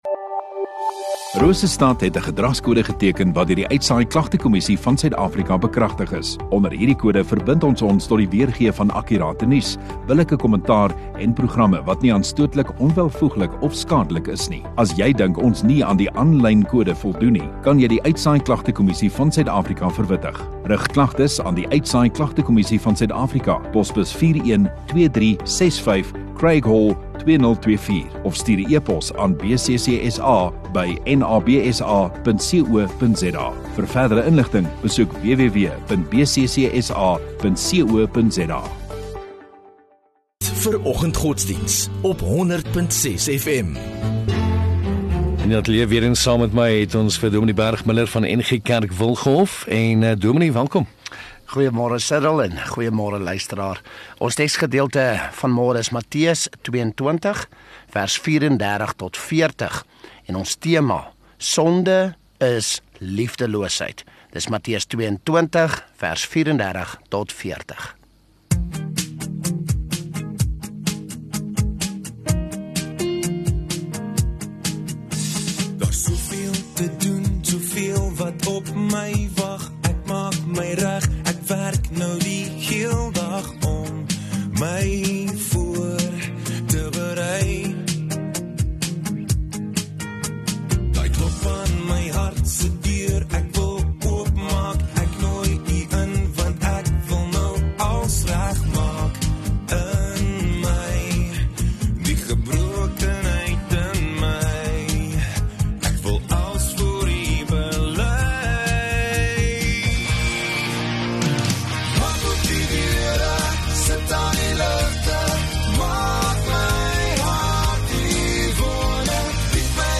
21 Sep Donderdag Oggenddiens